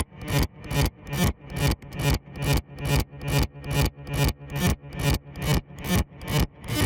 Korg Polysix F X " 低速加速华音
描述：厚实、丰富、和声的低音音色，带有加速的滤波器截止调制，来自原始的模拟Korg Polysix合成器，
Tag: 模拟 寻呼 合唱 脂肪 过滤器 FX KORG polysix 扫描 合成器 华暖